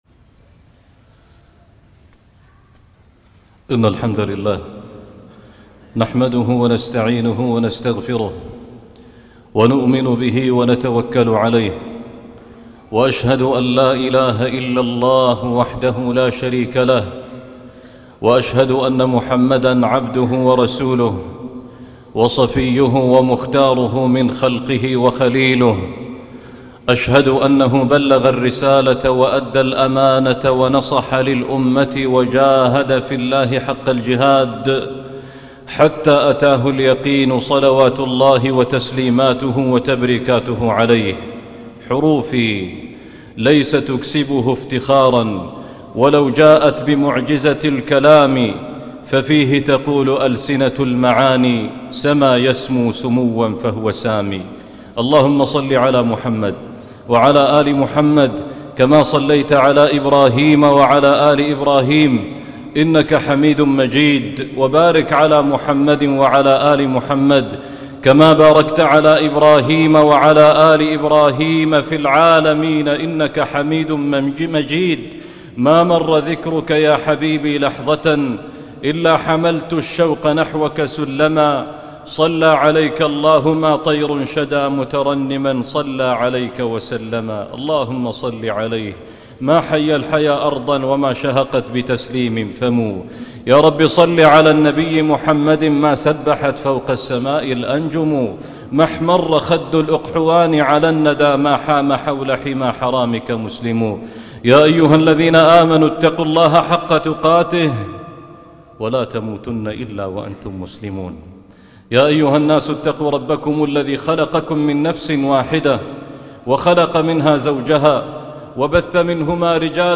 العبادة هي الحل - خطبة الجمعة